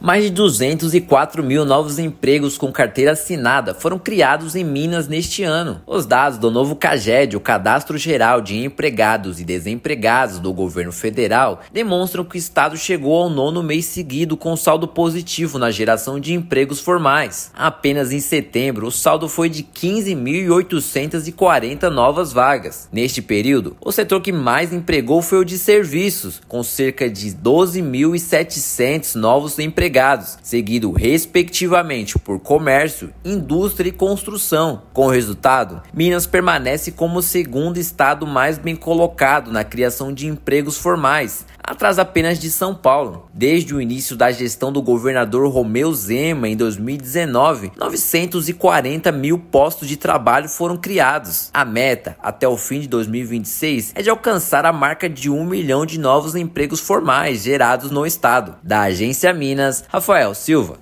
[RÁDIO] Minas já acumula 204 mil empregos com carteira assinada neste ano
Setembro confirma tendência de crescimento com mais de 15 mil novas vagas geradas no estado; de 2019 até agora já são 940 mil postos de trabalho formais. Ouça matéria de rádio.